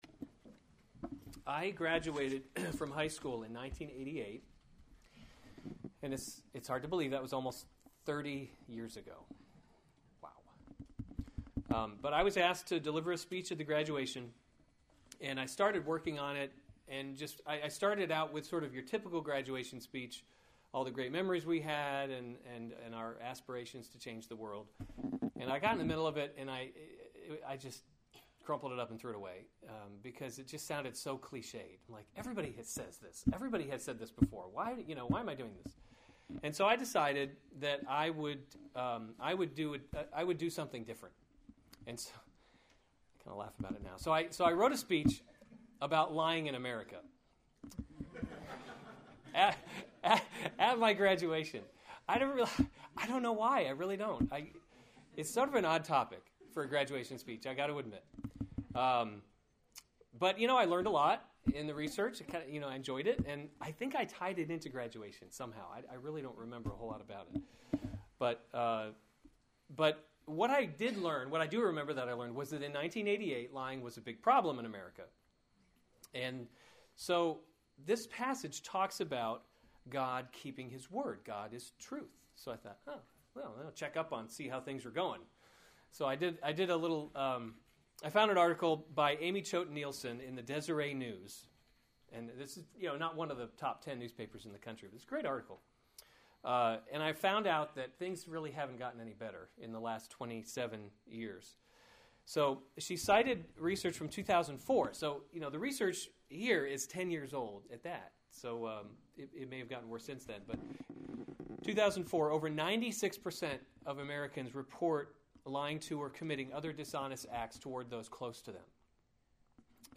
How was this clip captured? May 2, 2015 Romans – God’s Glory in Salvation series Weekly Sunday Service Save/Download this sermon Romans 15:8-13 Other sermons from Romans Christ the Hope of Jews and Gentiles 8 For […]